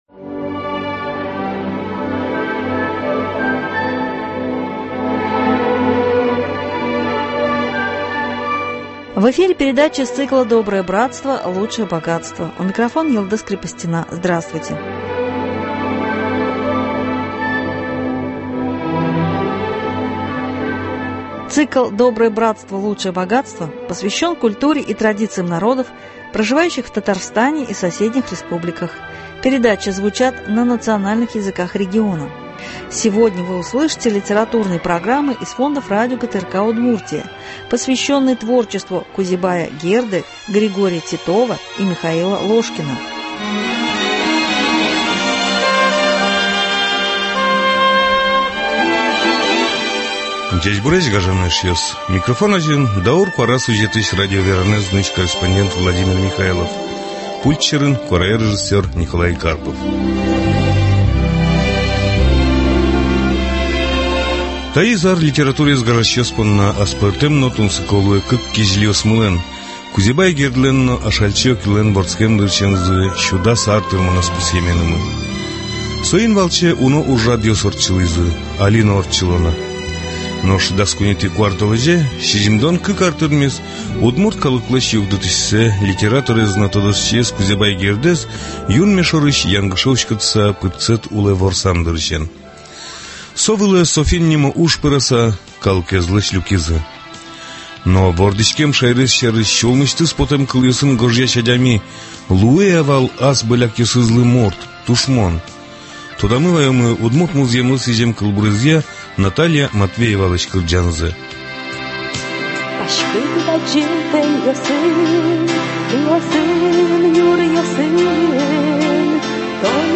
Сегодня вы услышите литературные программы из фондов радио ГТРК Удмуртия, посвященные творчеству Кузебая Герды, Григория Титова, Михаила Ложкина (на удмуртском языке).